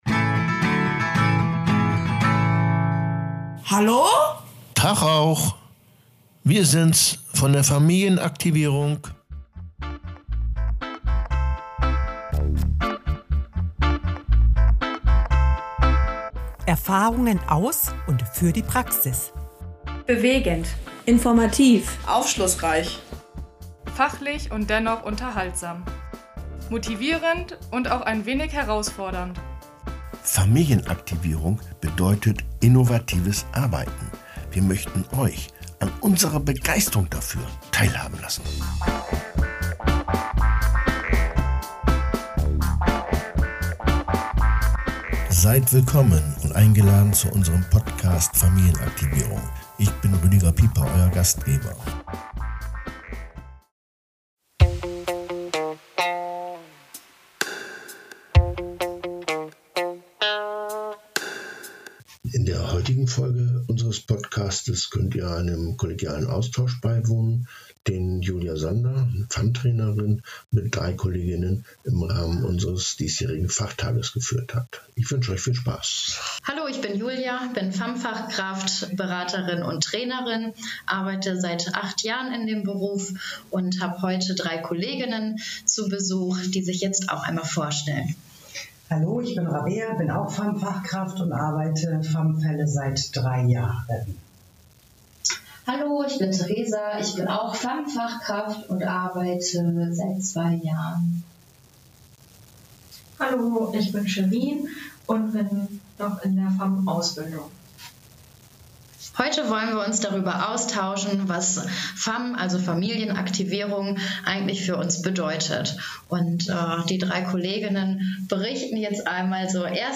Der jährliche Fachtag des Dachverbandes Familienaktivierung bietet den Fachkräften u.a. die Möglichkeit des kollegialen Austausches. In dieser Folge werden Kolleginnen mit unterschiedlichen Praxiserfahrungen zu dem, was FAM für sie ausmacht von einer FAM-Trainerin dazu befragt.